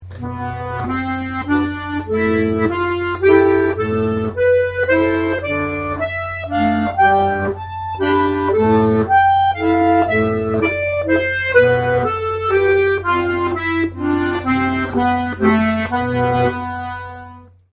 Accordeon diatonique et Musiques Traditionnelles
puis en mettant les accords main gauche
Gamme de DO en partant du si